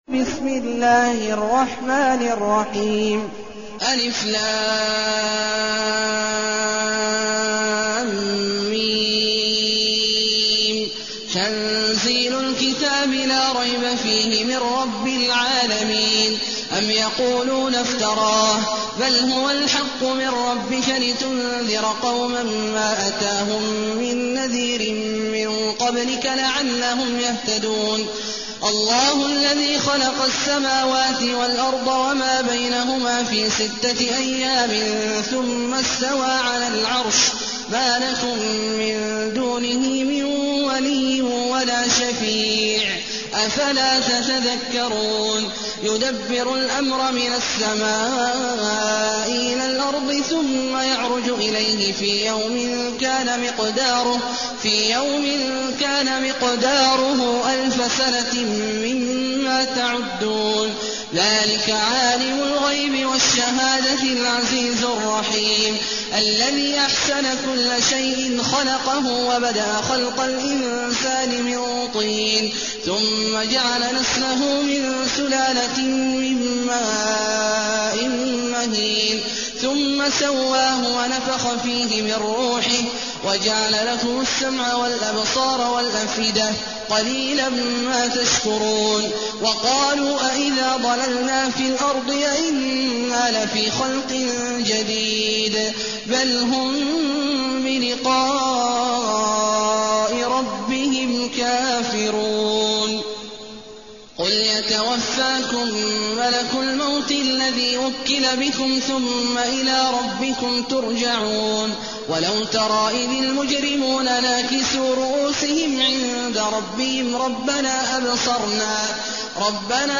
المكان: المسجد الحرام الشيخ: عبد الله عواد الجهني عبد الله عواد الجهني السجدة